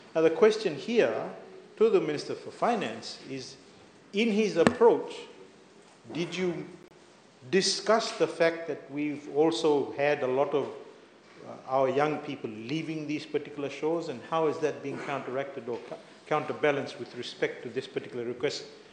Opposition MP Faiyaz Koya further questioned Prasad on the impact visa-free travel will have on the labor mobility schemes.